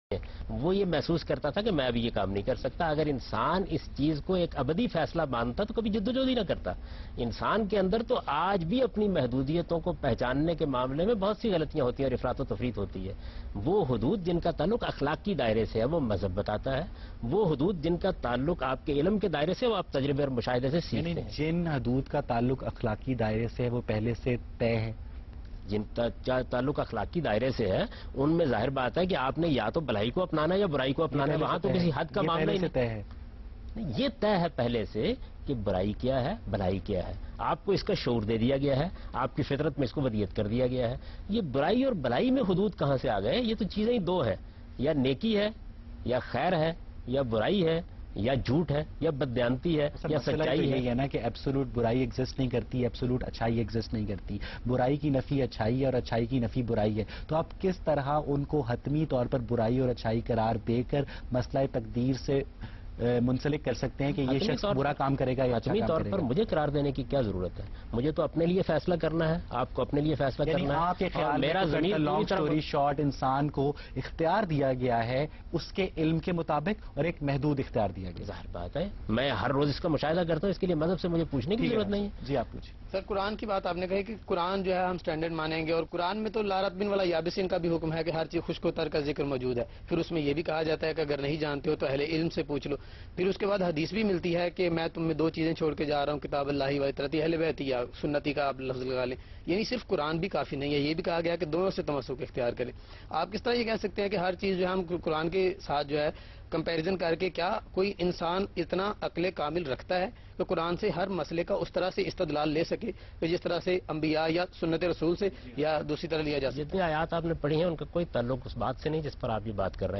Category: TV Programs / Geo Tv / Ghamidi /
Questions and Answers on the topic “ The Determinism or Free will?” by today’s youth and satisfying answers by Javed Ahmad Ghamidi.